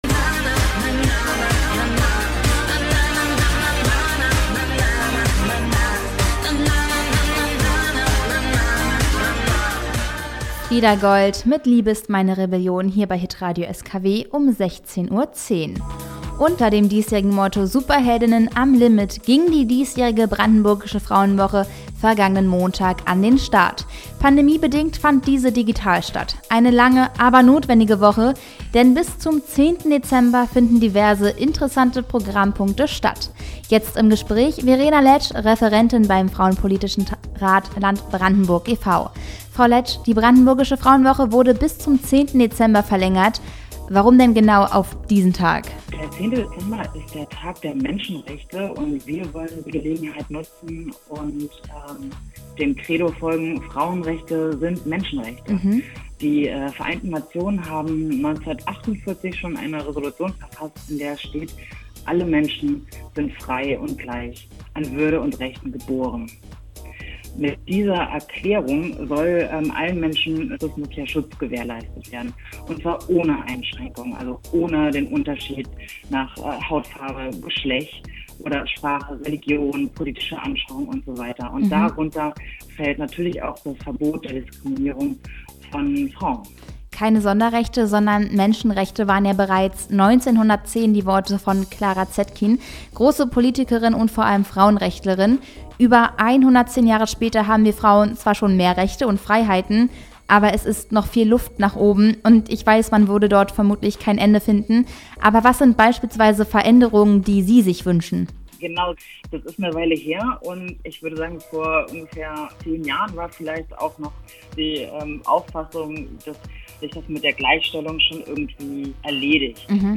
Radio SKW zur 31. Brandenburgischen Frauenwoche 2021 im Gespräch mit